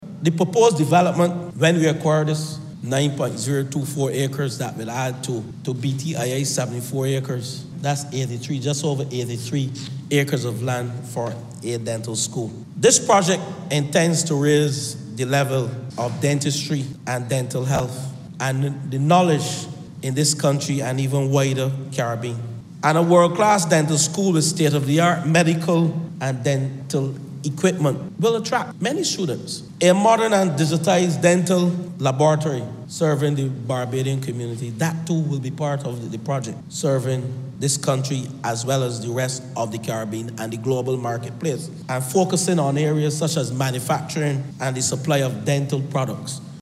The plan was outlined by the Minister of Housing Lands and Maintenance, Dwight Sutherland, as he introduced in the House of Assembly a resolution to compulsorily acquire land at Harrisons Plantation for education, tourism and economic development.
Minister of Housing Lands and Maintenance, Dwight Sutherland.